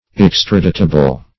Search Result for " extraditable" : The Collaborative International Dictionary of English v.0.48: Extraditable \Ex"tra*di`ta*ble\, a. 1.